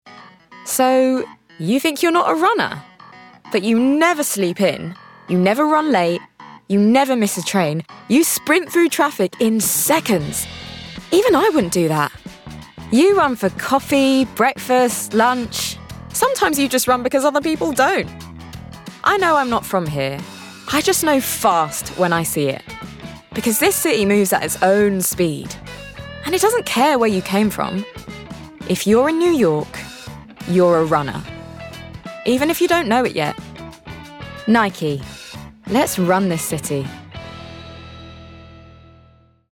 Nike - Empowered, Fun, Dynamic